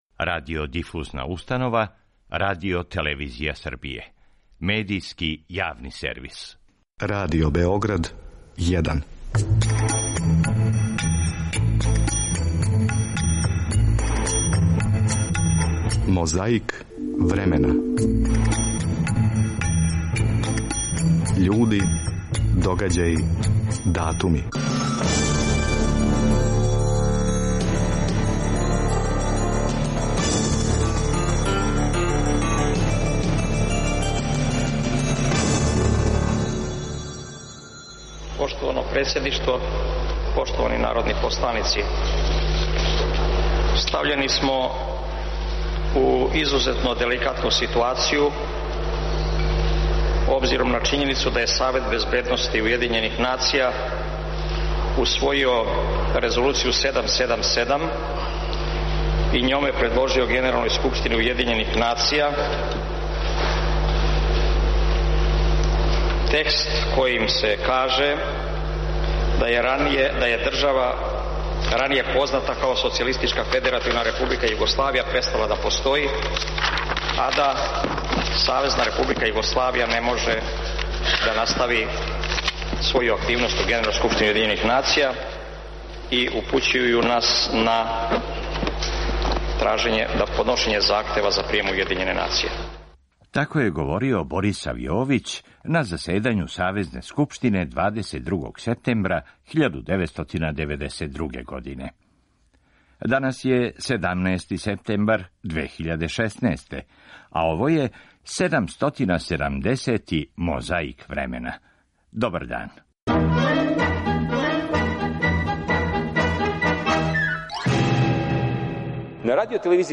Slobodan Milošević govorio je na mitingu u Beranama 20. septembra 2000. godine.
Mikrofoni rade, magnetofoni snimaju.